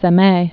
(sĕ-mā) or Sem·i·pa·la·tinsk (sĕmē-pə-lätĭnsk)